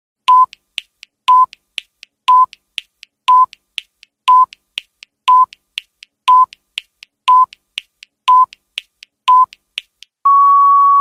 جلوه های صوتی
دانلود آهنگ ثانیه شمار 3 از افکت صوتی اشیاء
دانلود صدای ثانیه شمار 3 از ساعد نیوز با لینک مستقیم و کیفیت بالا